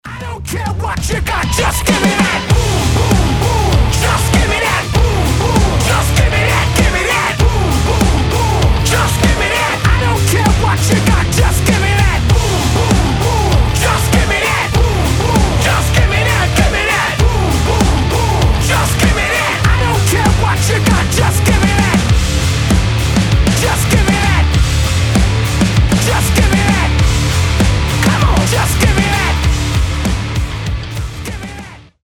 громкие
мощные
брутальные
nu metal
взрывные
бодрые
злые
метал